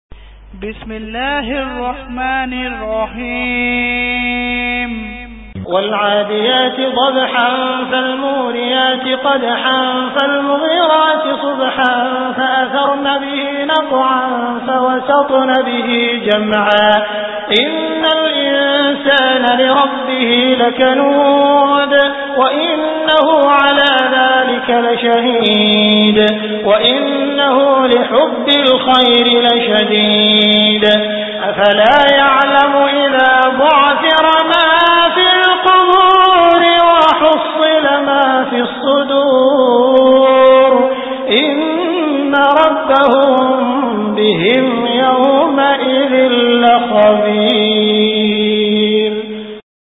Surah Al Adiyat Beautiful Recitation MP3 Download By Abdul Rahman Al Sudais in best audio quality.